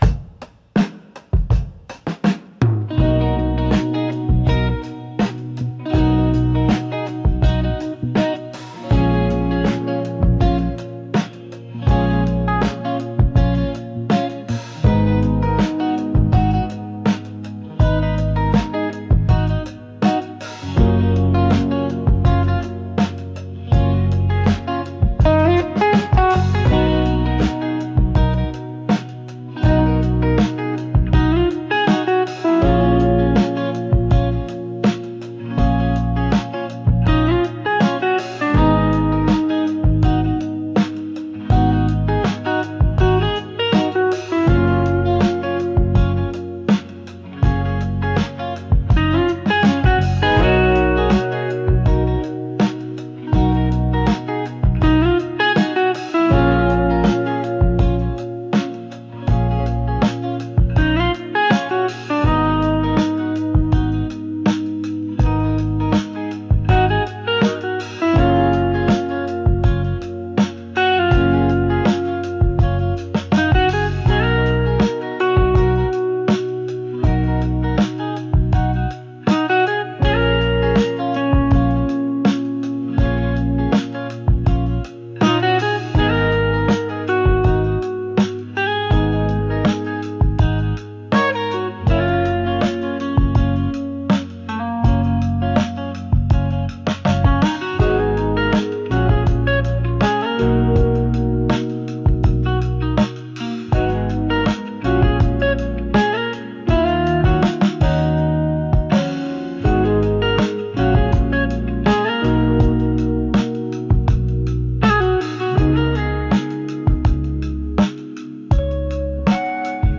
大人な雰囲気